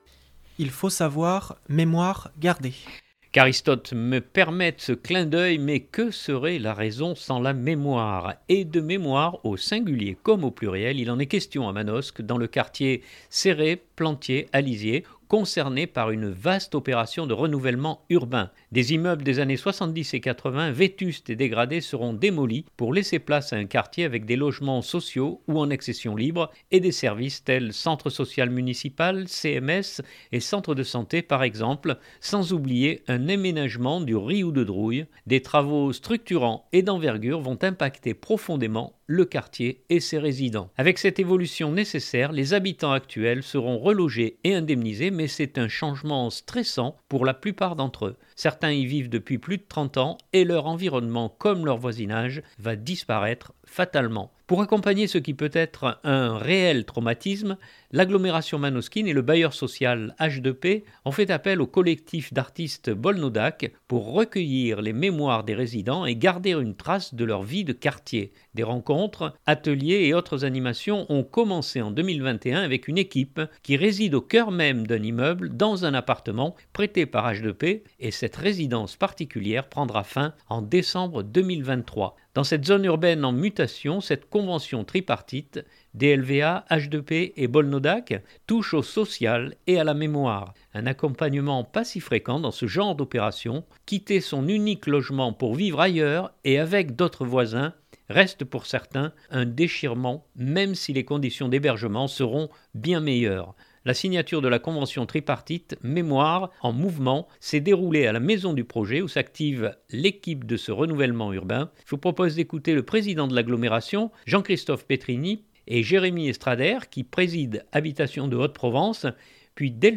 La signature de la convention tripartite « Mémoire(s) en mouvement » s’est déroulée à la Maison du projet où s’active l’équipe de ce renouvellement urbain.